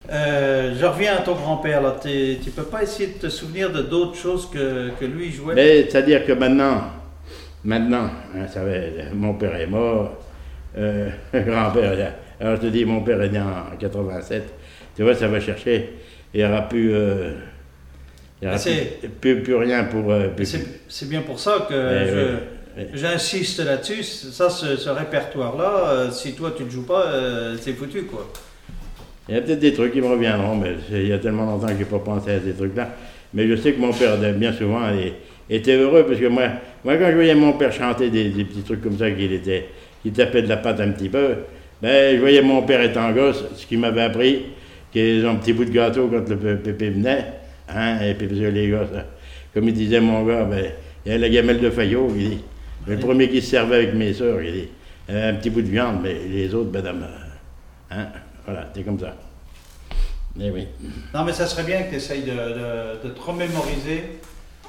Témoignage sur la musique et des airs issus du Nouc'h
Catégorie Témoignage